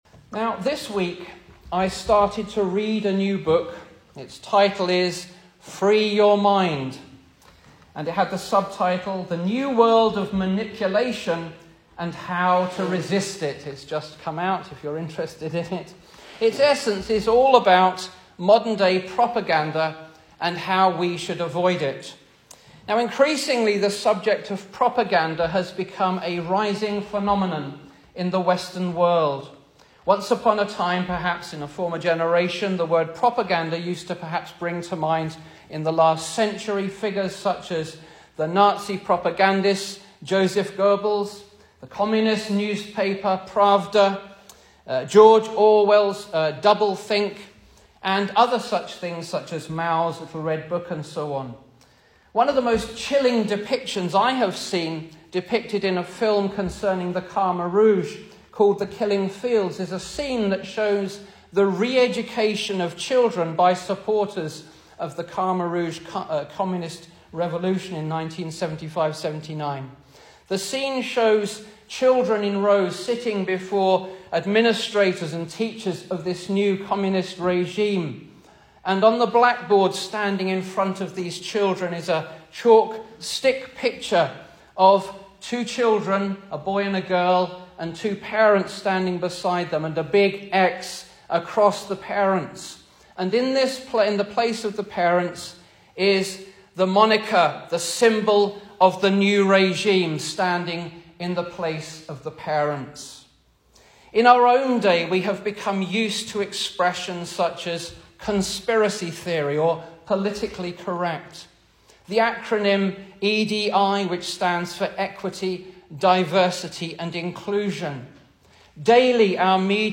Service Type: Sunday Evening
Single Sermons